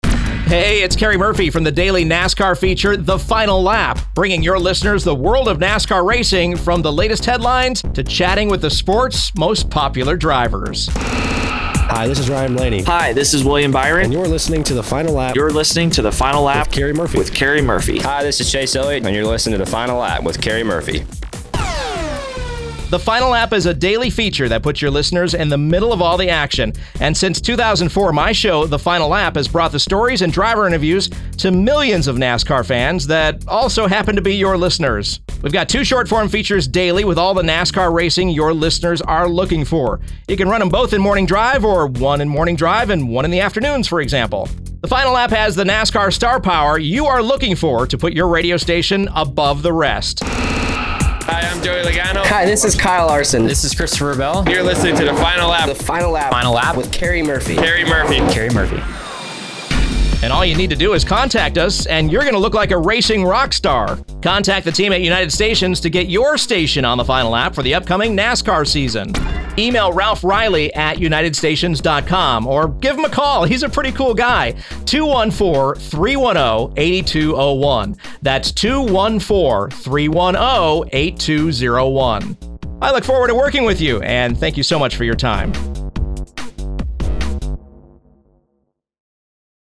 Rock/Country/Sports :90 second Vignette About the show Daily racing news in just 90 seconds!
Creatively written with powerful production and a unique spin on racing news